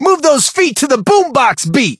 布洛克是一个广场舞男孩，他的语音和手提式音响（Boombox）有关。